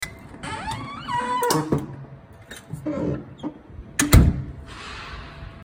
Download Door Opening sound effect for free.
Door Opening